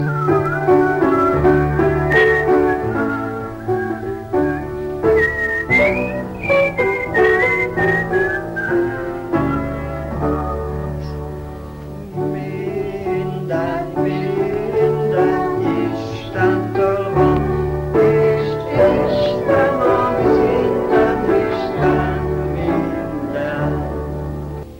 Magyar énekléssel (1990-es felvétel):